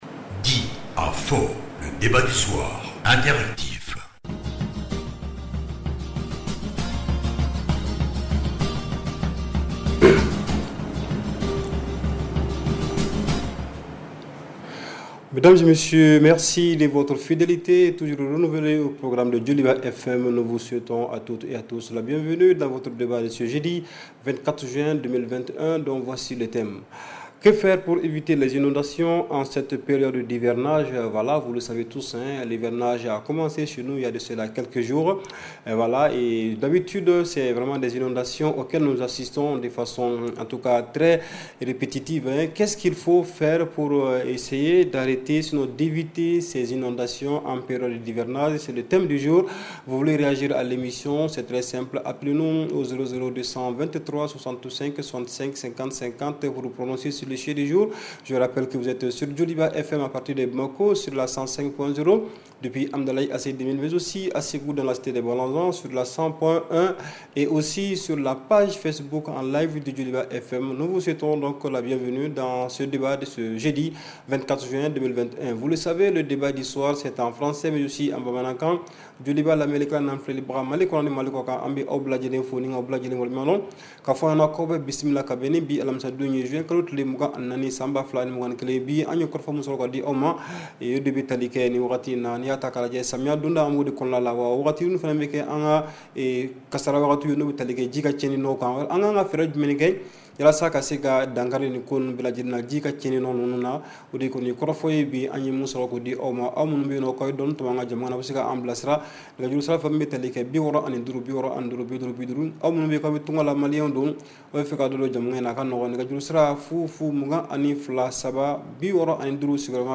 REPLAY 24/06 – « DIS ! » Le Débat Interactif du Soir